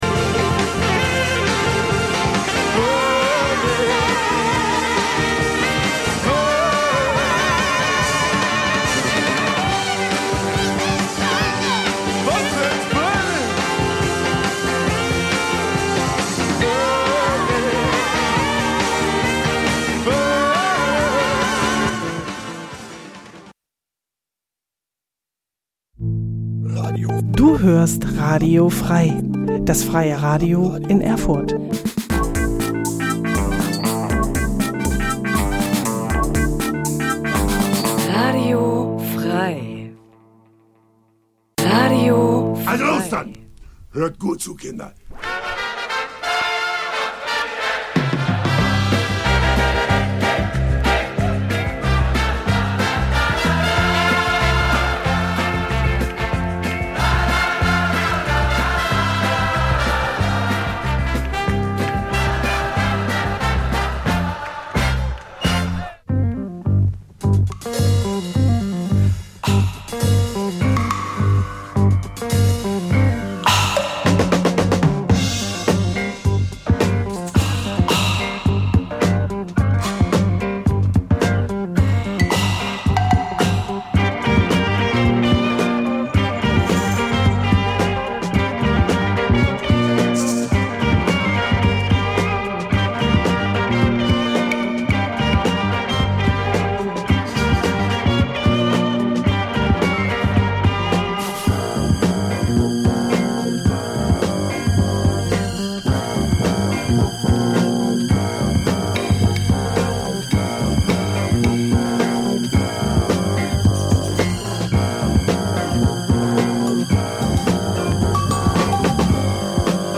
Trotzdem lassen wir es uns nicht nehmen immer wieder sonntags ein paar ganz besondere Perlen unserer Schallplattensammlung einer m�den aber durchaus interessierten H�rer-schaft zu pr�sentieren. Hierbei handelt es sich ausschlie�lich um leicht bek�mmliche Musikst�cke aus dem Be-reich Jazz, Soul, Funk, Soundtracks, Beat, French Pop u.s.w. Ganz nebenbei geben wir dem H�rer Informationen zu den gespielten Musikst�cken und vermitteln ihnen wertvolle Tips zu Bew�ltigung des